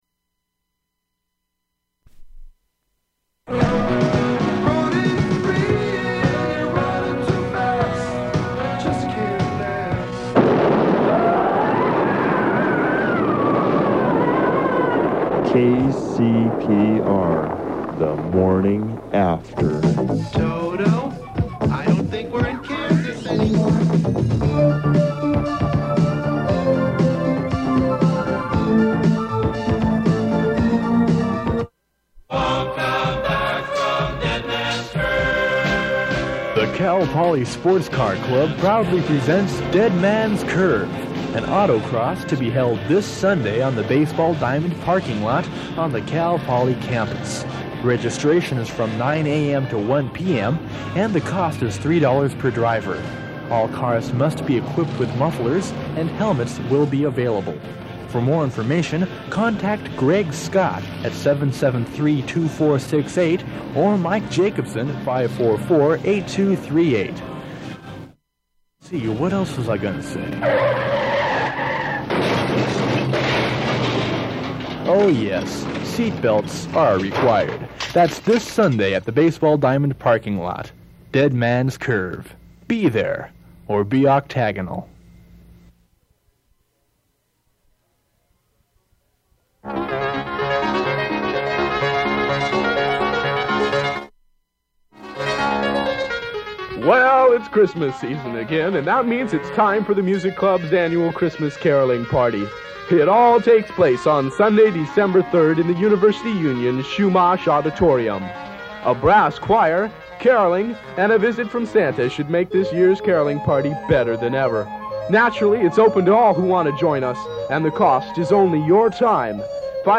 Compilation of spots recorded for broadcast on KCPR between 1978 to 1979
The Morning After' spot - the explosion sound related to ABC's show 'The Day After'